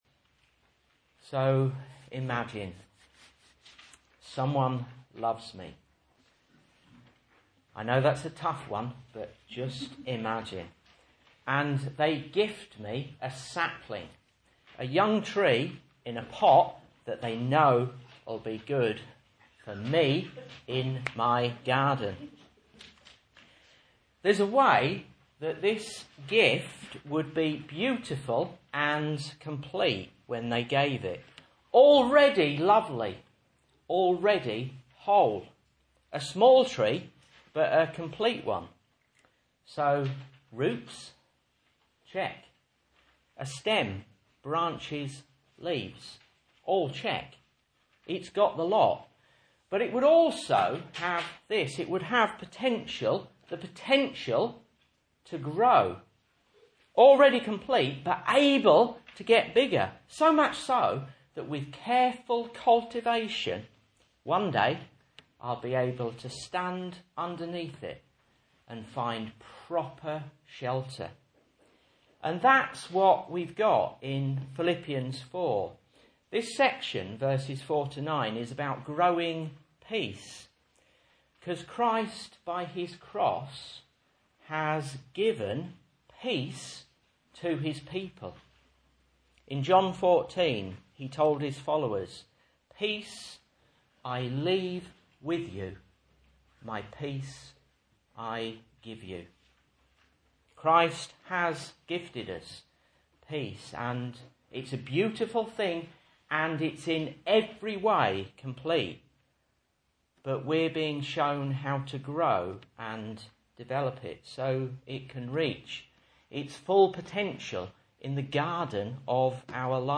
Message Scripture: Philippians 4:4-9 | Listen